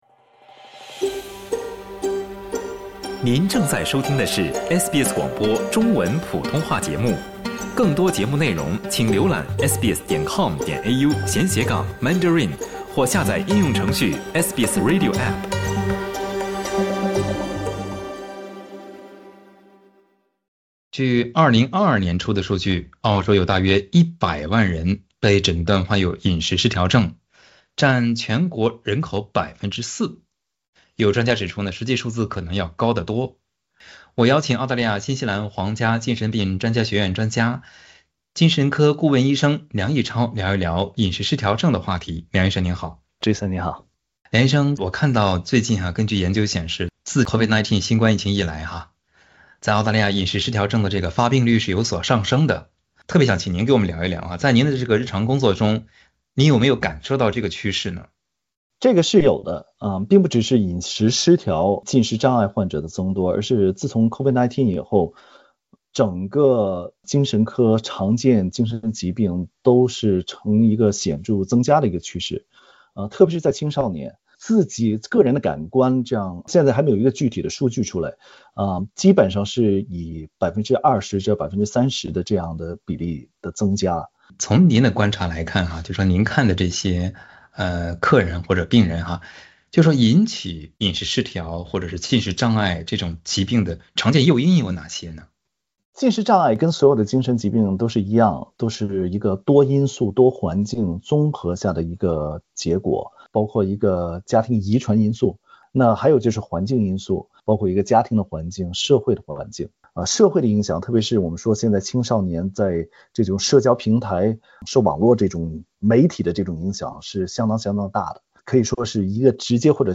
营养师建议及早重视“饮食行为异常” 为了预防患上或发展成这种严重的疾病，潜在患者应该怎么做？其亲友应该怎样介入比较好呢？在接受SBS普通话采访时